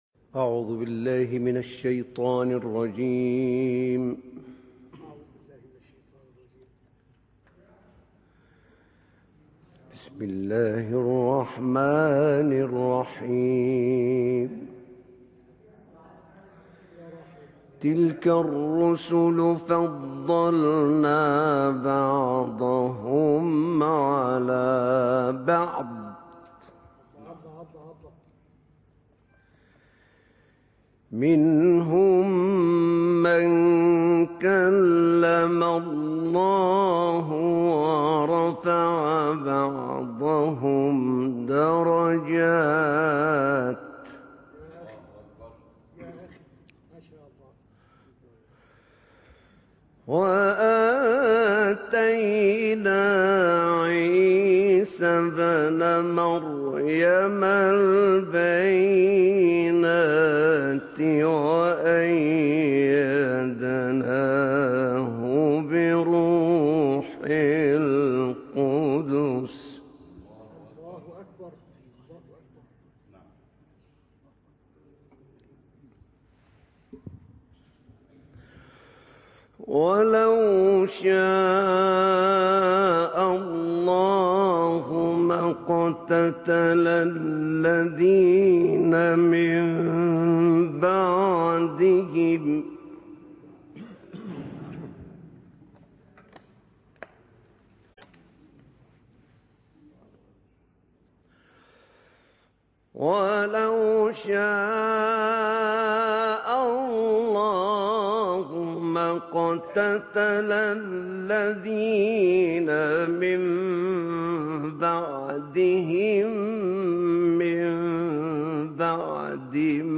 تلاوتی جدید از سوره بقره با صوت «احمد نعینع»
گروه شبکه اجتماعی: تلاوت آیاتی از سوره مبارکه بقره که به تازگی در تلاوت سحرگاهی توسط «احمد نعینع» اجرا شده است، می‌شنوید.
این تلاوت هفته گذشته پنجم آوریل در مراسم سحرگاهی (تلاوت قبل از اذان صبح) در مسجد سیده نفسیه (از نوادگان امام حسن مجتبی(ع)) شهر قاهره انجام شده و مدت زمان آن 23 دقیقه است.